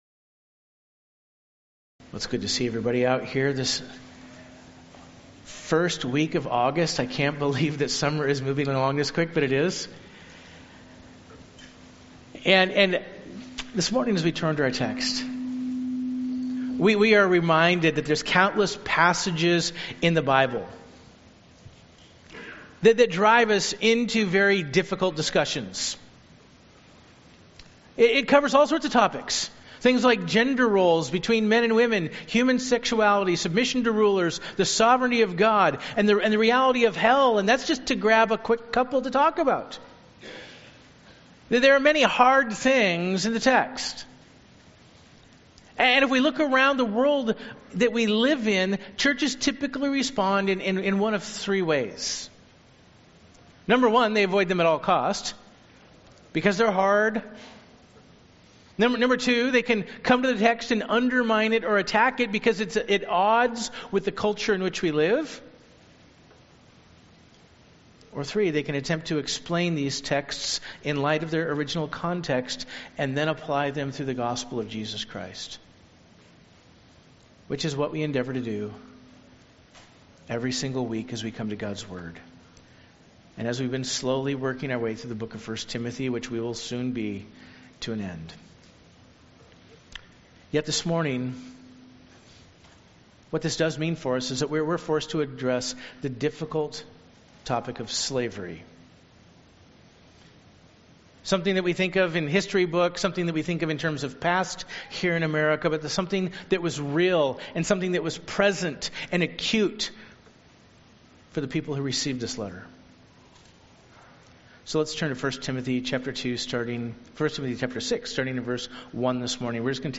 Sermon Outline: I. The Practice of Slavery in the First Century A.D. A. Slavery in America and Rome B. Slavery and the Apostle Paul II.